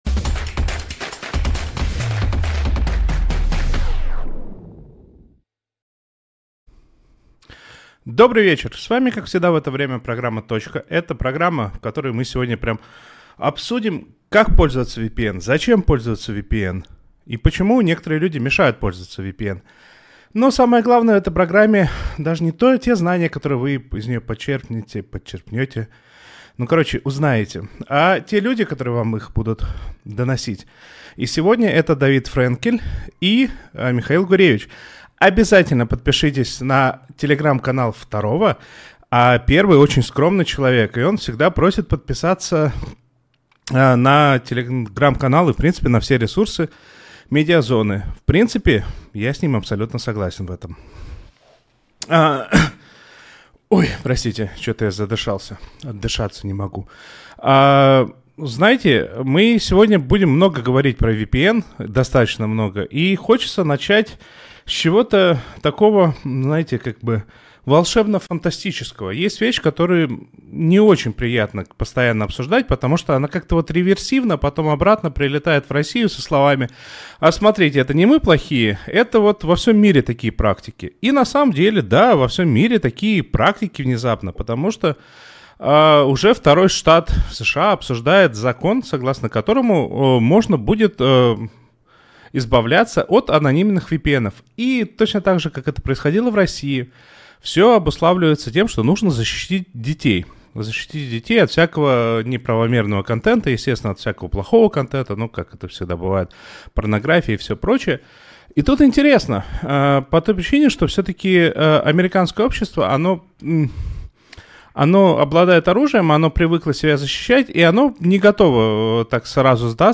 говорит с экспертами про интернет и технологии в нашей жизни